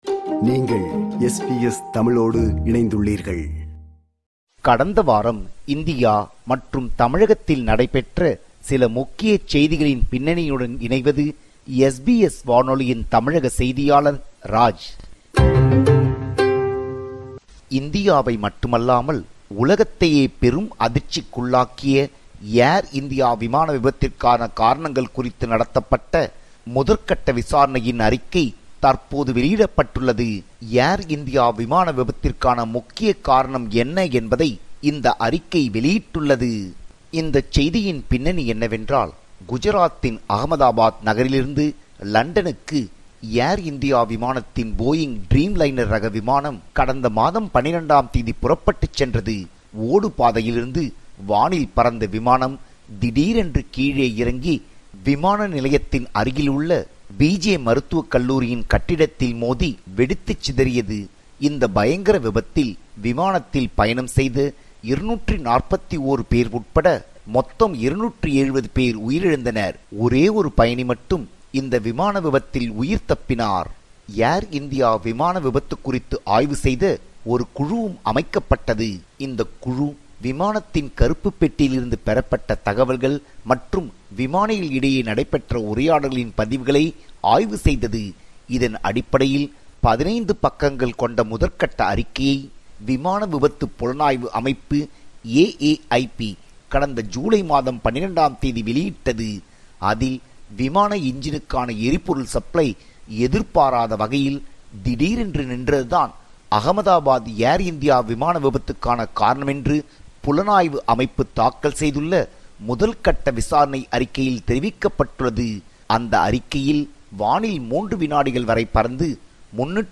இணைகிறார் நமது தமிழக செய்தியாளர்